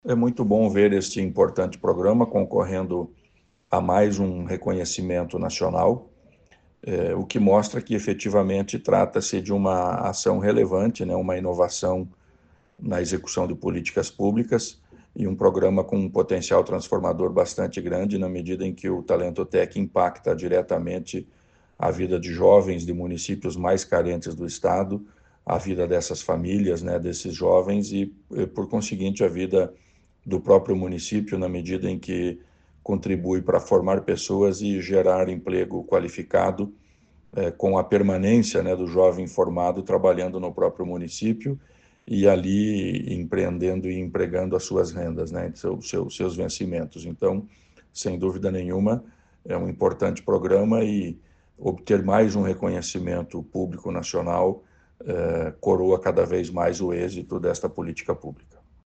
Sonora do secretário da Ciência, Tecnologia e Ensino Superior, Aldo Bona, sobre o Talento Tech finalista em prêmio nacional